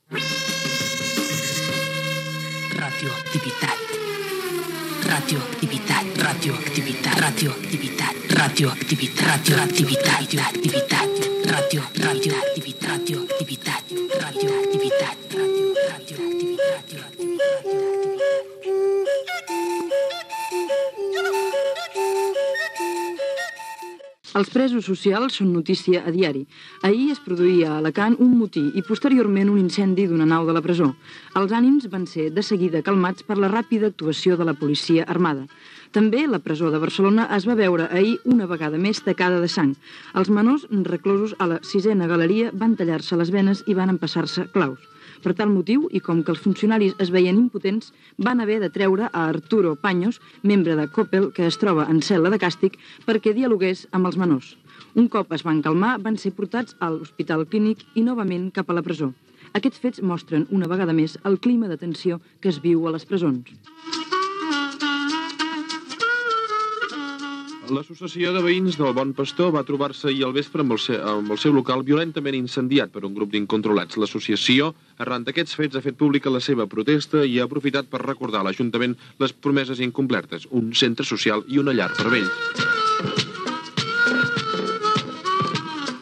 Indicatiu del programa, informació dels aldarulls a les presons d'Alacant i Barcelona, de presos socials, i de l'incendi al local de l'Associació de Veïns del Bon Pastor a Barcelona